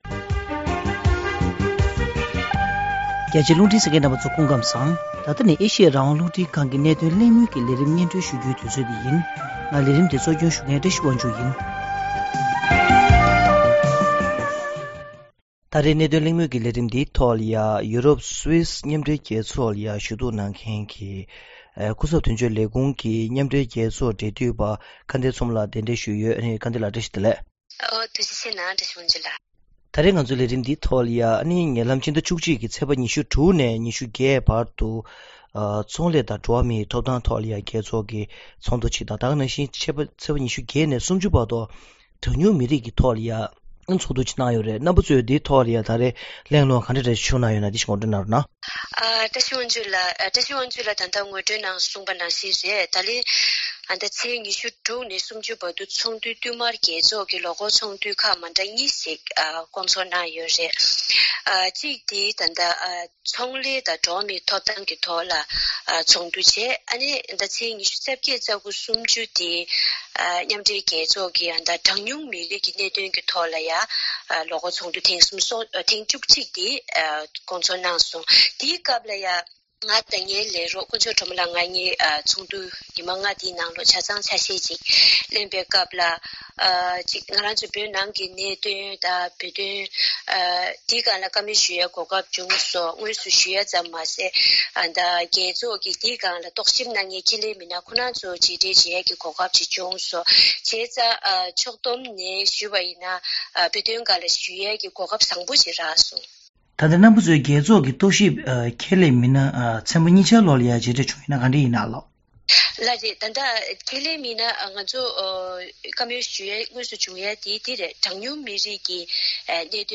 གླེང་མོལ་གྱི་ལས་རིམ